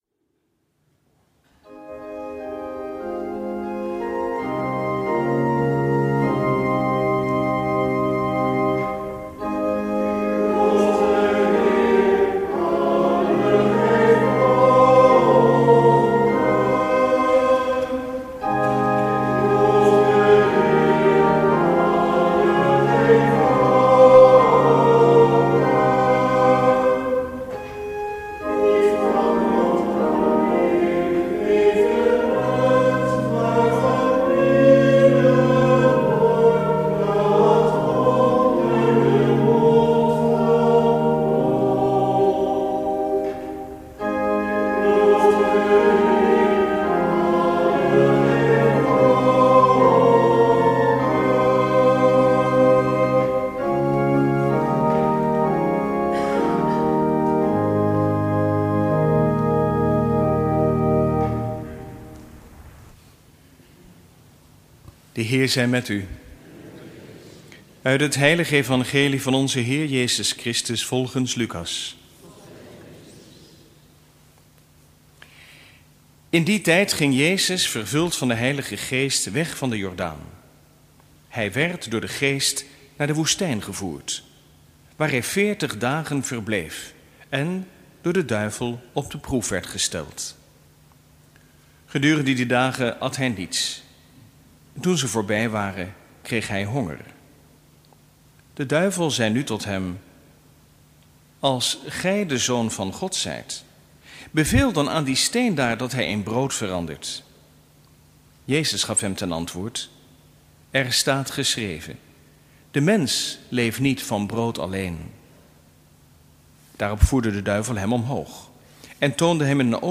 Lezingen
Eucharistieviering beluisteren vanuit de St. Jozefkerk te Wassenaar (MP3)